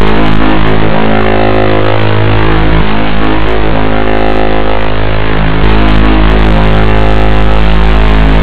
（※音声ファイルの再生は非推奨，ノイズ音が流れます）